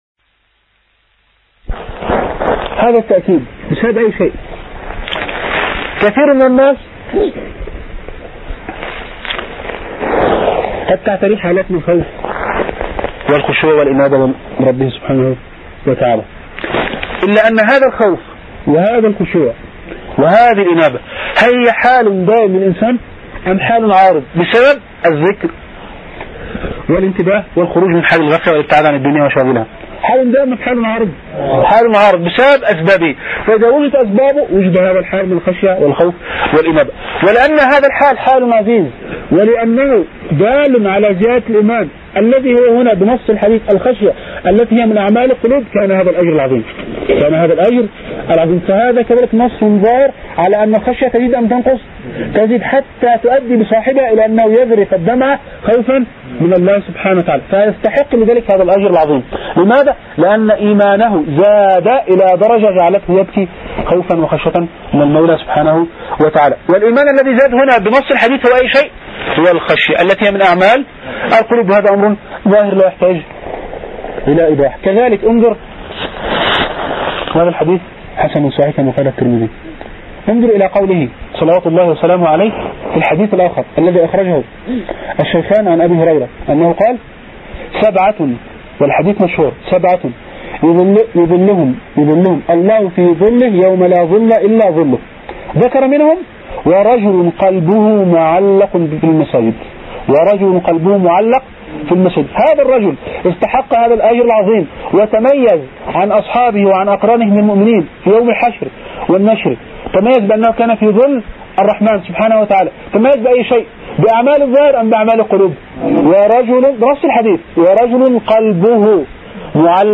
مناقشة كتاب ' الدارس في تاريخ المدارس ' لعبد القادر النعيمي -.mp3